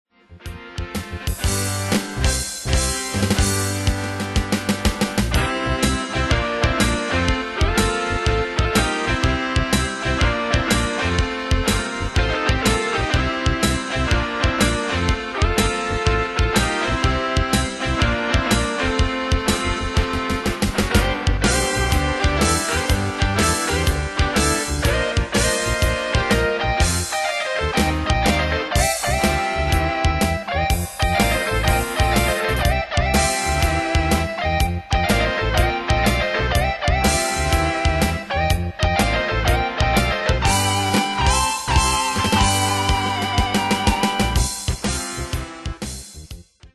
Style: Pop Rock Année/Year: 1974 Tempo: 123 Durée/Time: 4.29
Danse/Dance: Rock Cat Id.
Pro Backing Tracks